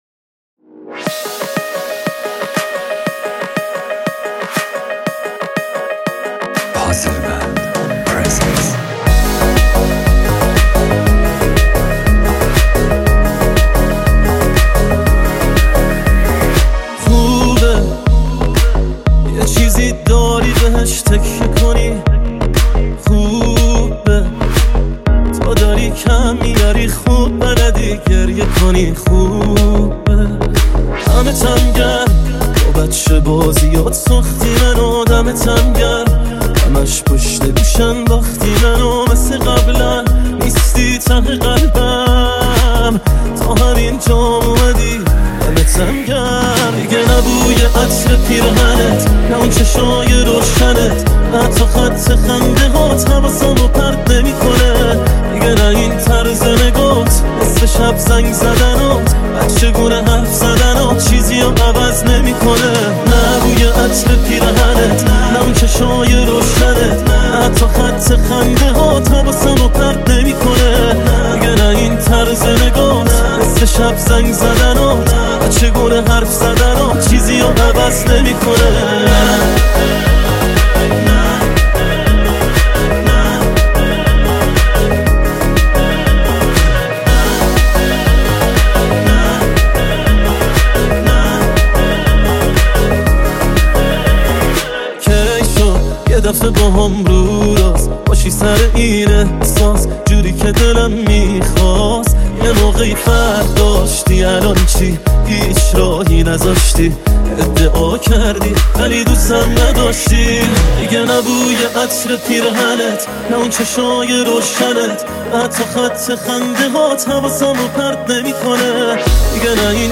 ریمیکس ارکستی بندی همراه با نی انبان
ریمیکس شاد ارکستی
ریمیکس شاد و بندری مخصوص رقص